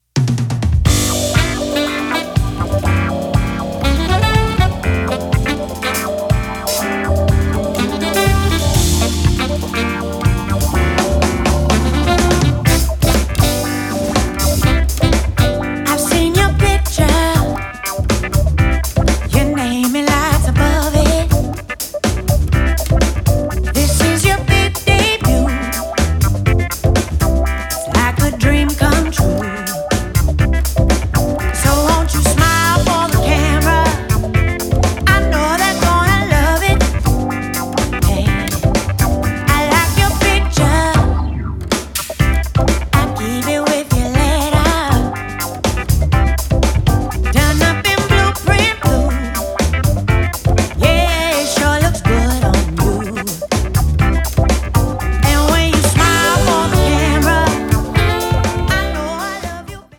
double bass
piano and keyboards
drums
alto sax and percussions
Recorded at Sorriso Studio